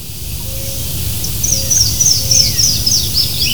Yellow-throated Warbler
Dendroica dominica
VOZ: El llamado es un agudo y sonoro "sii".